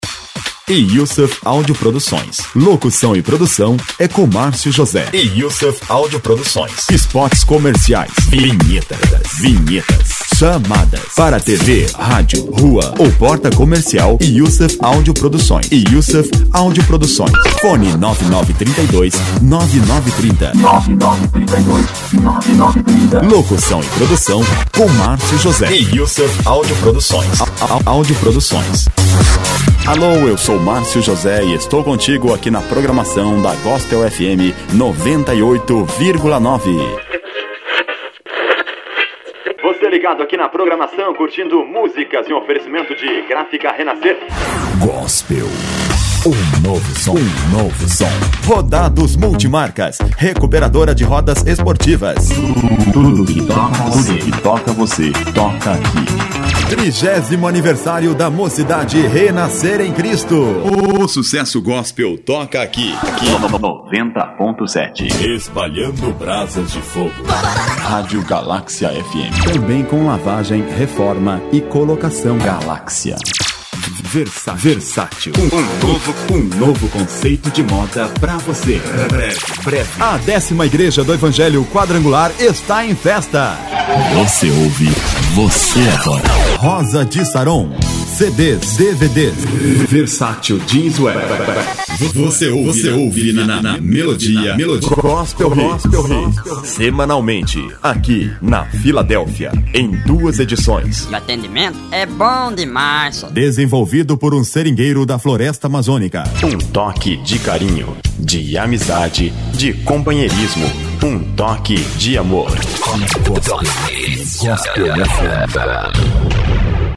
Masculino